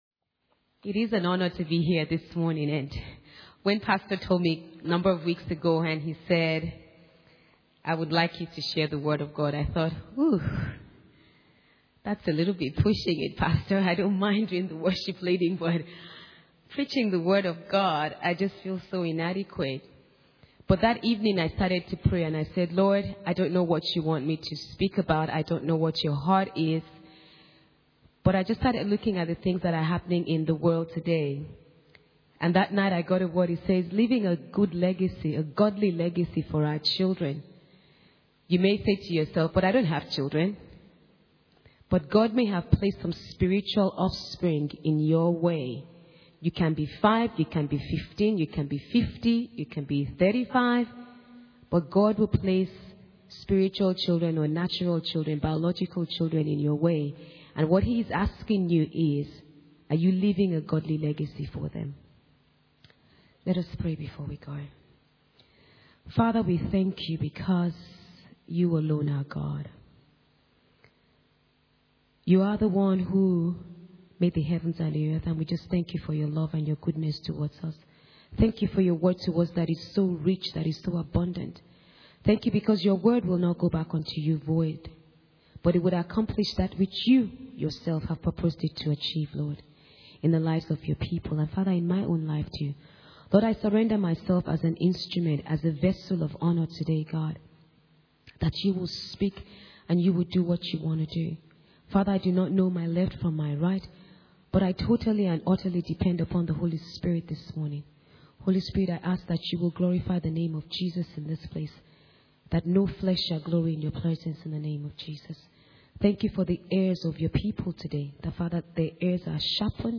Sunday Morning « Holiness and Fear of God pt 10